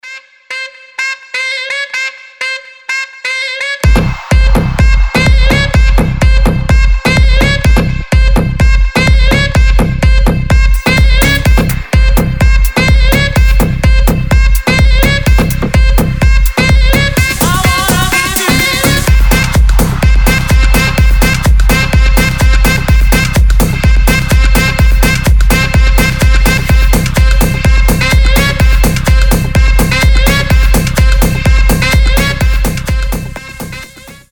• Качество: 320, Stereo
громкие
мощные
EDM
без слов
Стиль: electro house, big room